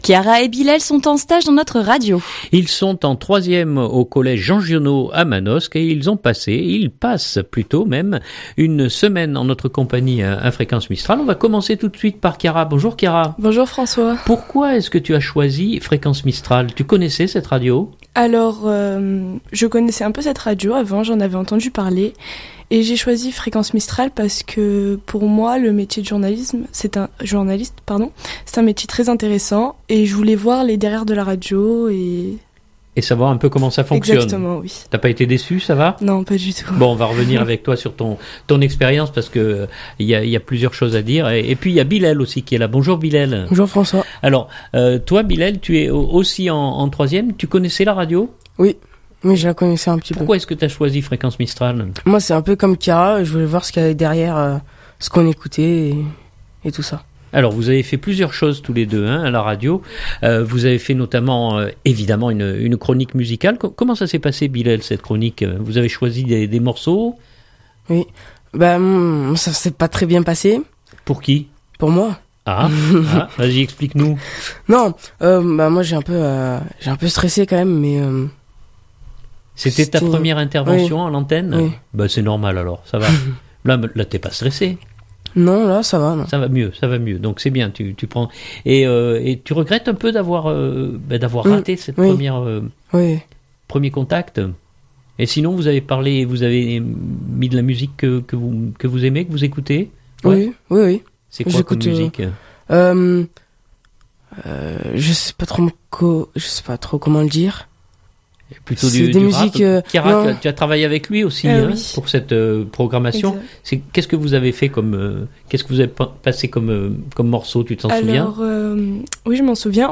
2019-11-28-Reportages Sur Nos Stagiaires.mp3 (4.9 Mo)